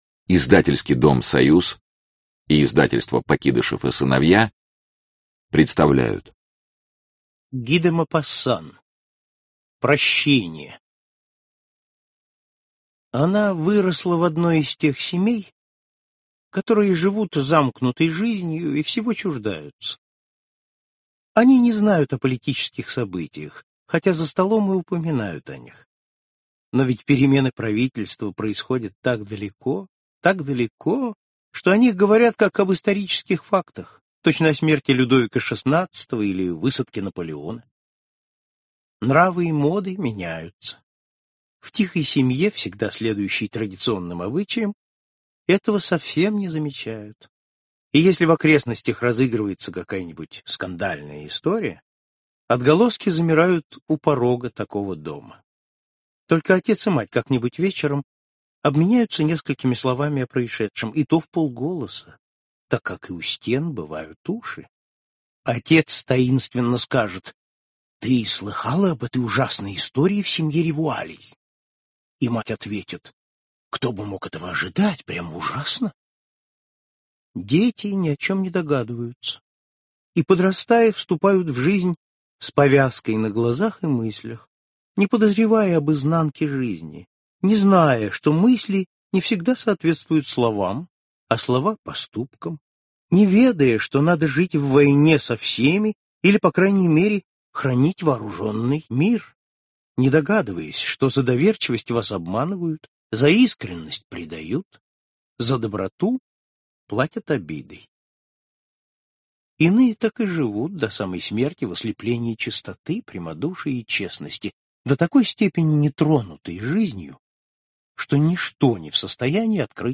Аудиокнига Парижское приключение | Библиотека аудиокниг